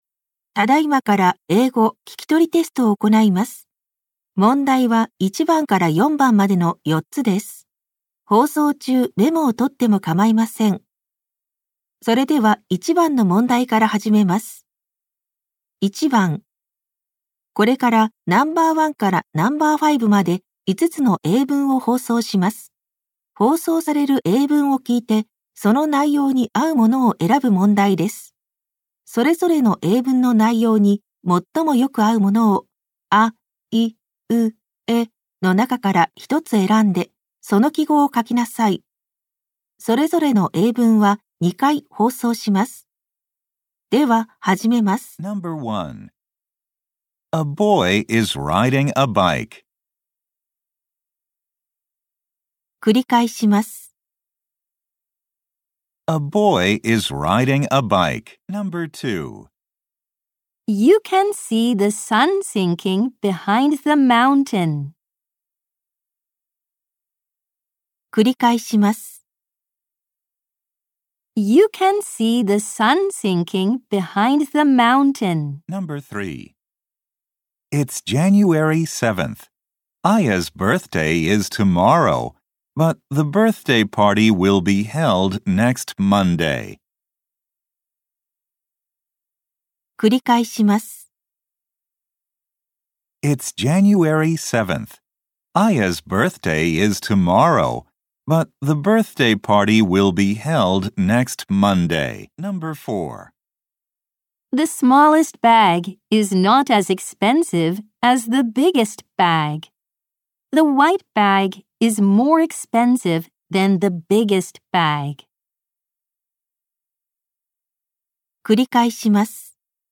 一般入試英語聞き取りテスト